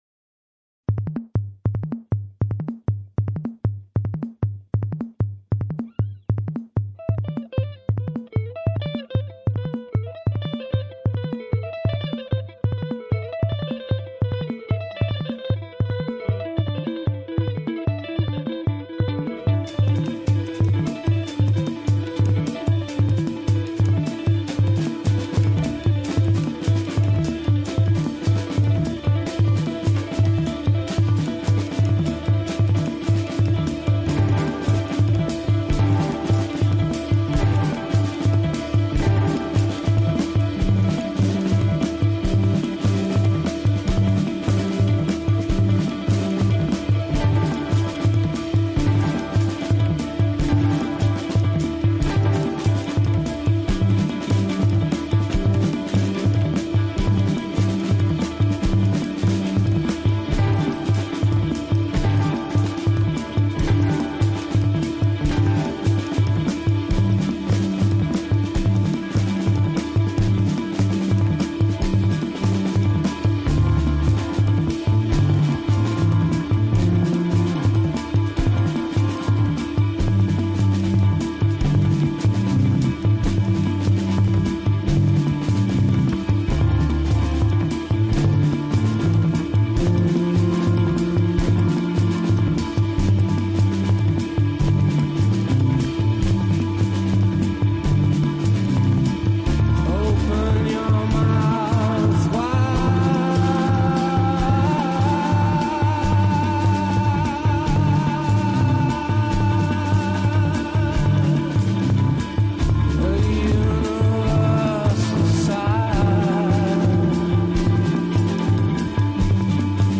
Alternative Rock, Electronic